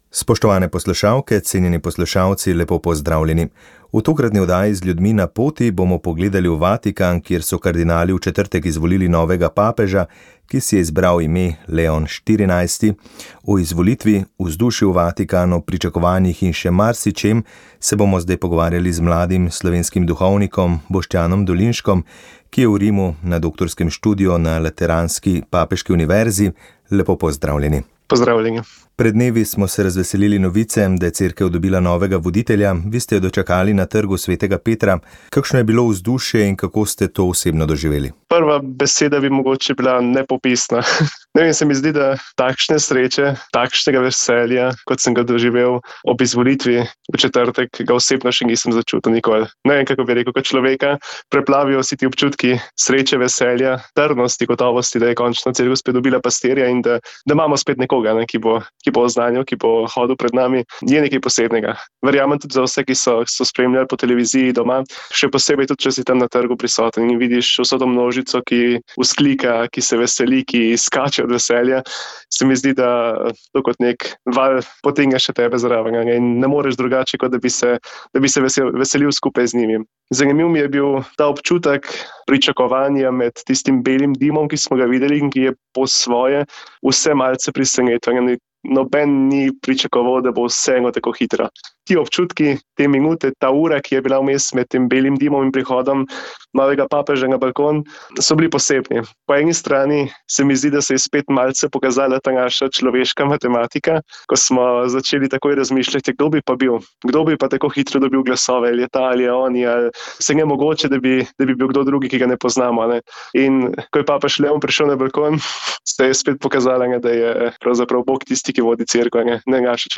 Pridiga nadškofa Antona Stresa na pogrebu Ivana Omana
V Škofji Loki so danes pokopali Ivana Omana, starosto slovenske politike in enega od ključnih osamosvojiteljev Slovenije ter soustanovitelja Slovenske kmečke zveze. Srčnost, pravičnost, pogum, humanizem so bile med besedami, ki jih je v pridigi pri pogrebni sveti maši izrekel nadškof Anton Stres.